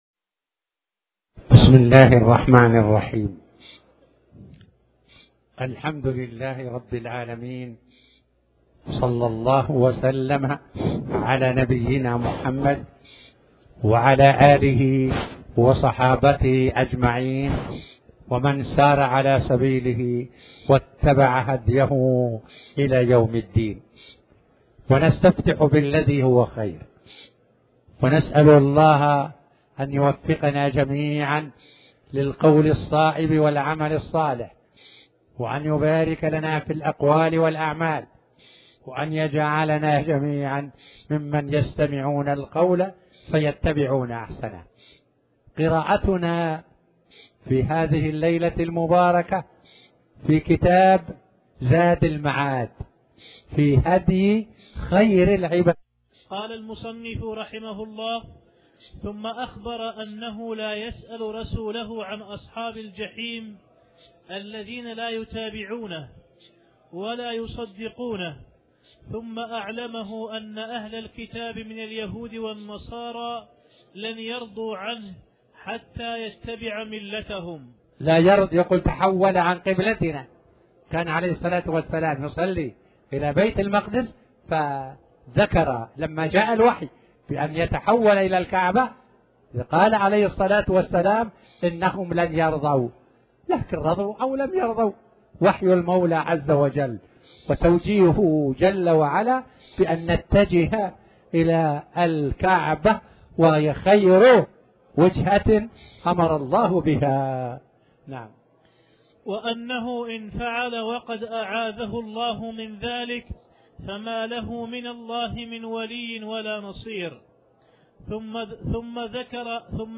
تاريخ النشر ١١ ذو القعدة ١٤٣٩ هـ المكان: المسجد الحرام الشيخ